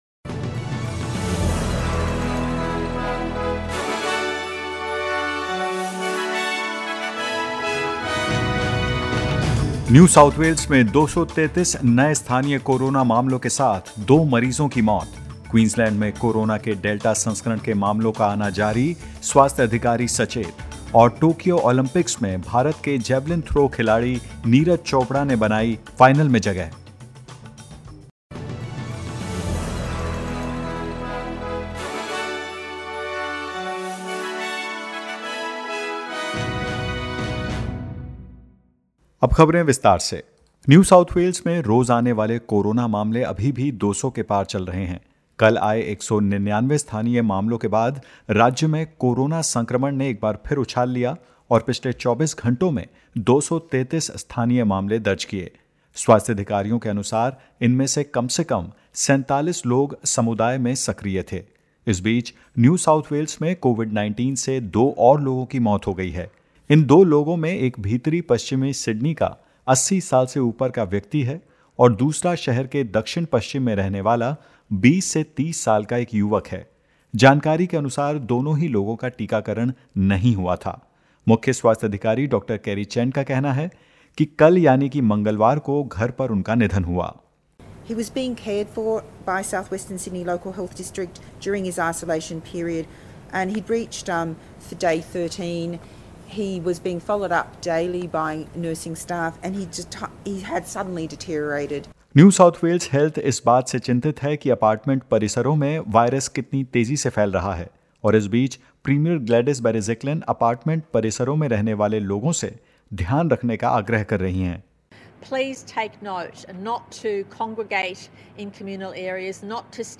In this latest SBS Hindi News bulletin of Australia and India: South Australia recorded one new case in the community; No new cases in Victoria for the first time in over three weeks and more.